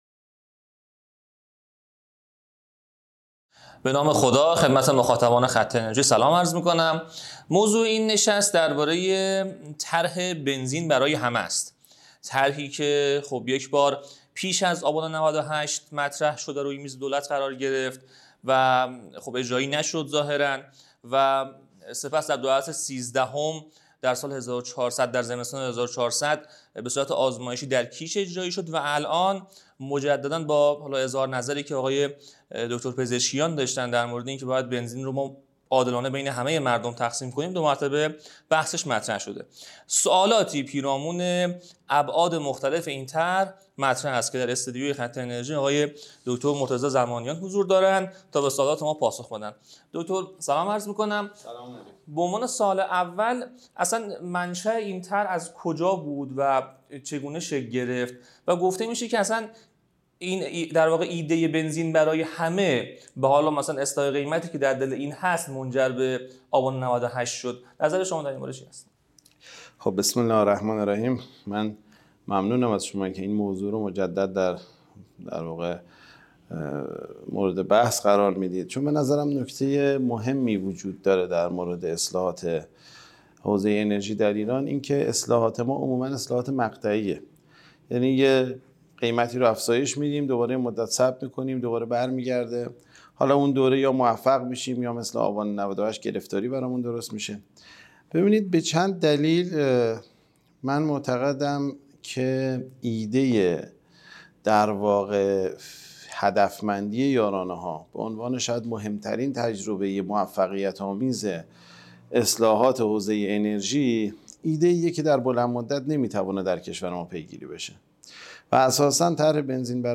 مصاحبه کامل را در یوتیوب خط انرژی ببینید.